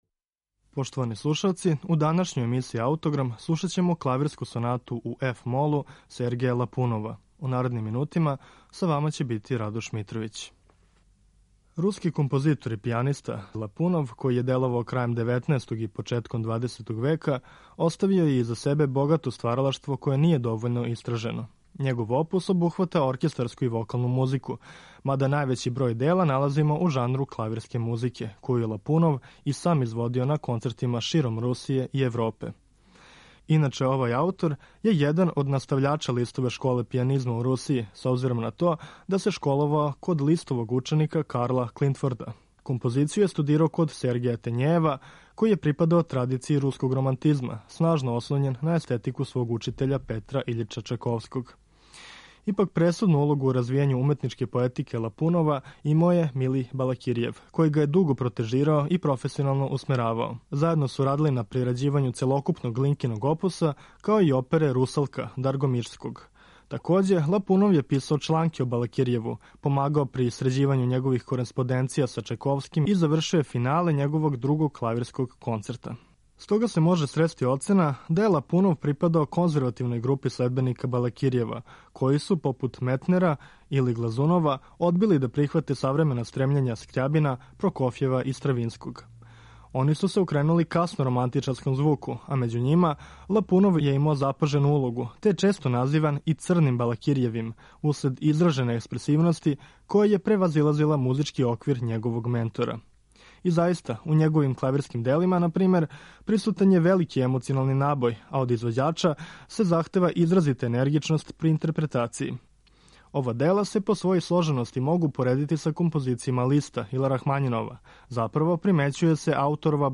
Сергеј Љапунов: Клавирска соната | Радио Београд 2 | РТС
У питању је монотематско дело, цикличне форме, позно романтичарског звука.